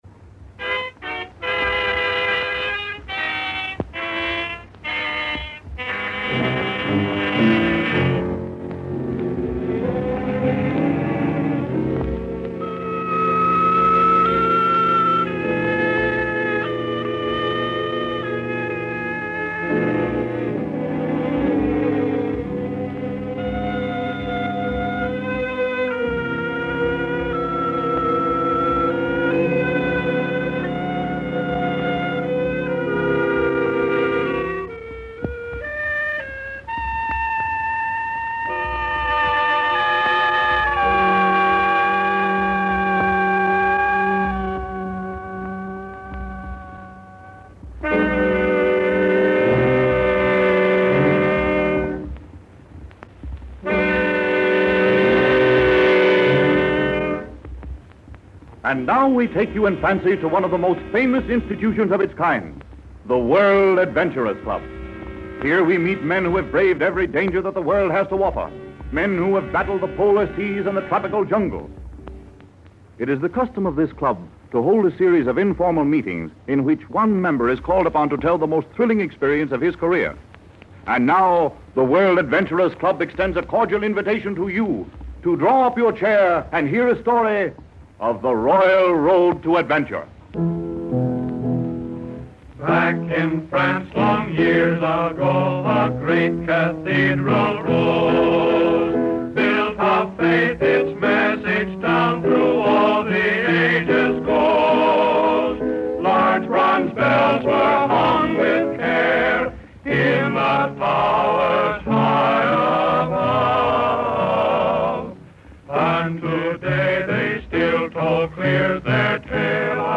This thrilling episode from the World Adventurers Club of 1932 transports listeners to the icy realms where adventure awaits at every treacherous turn. The World Adventurers Club, a radio series from the early Golden Age of radio, offered its audience an escape to exotic places and extraordinary events.